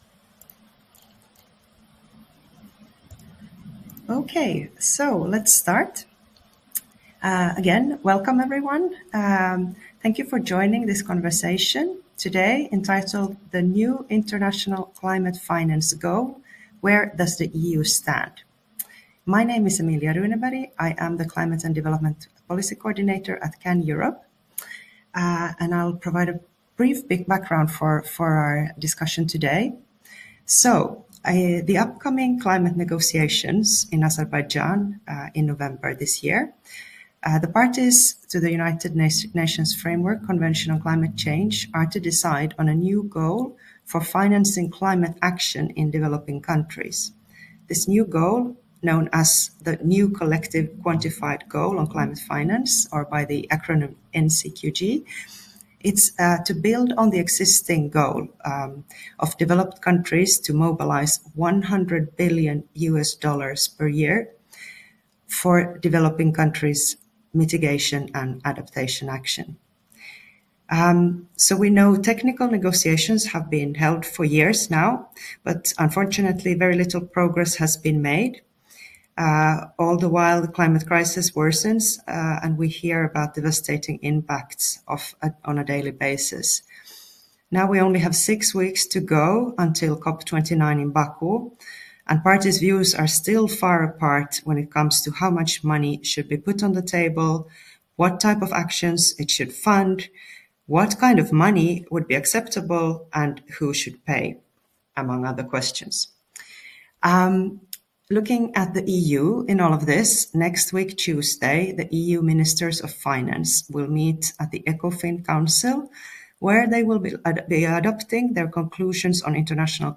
Listen to a live broadcast where a global panel of experts discussed the anticipated EU decision ahead of negotiations for a new international climate finance goal, which countries are to agree upon at COP29.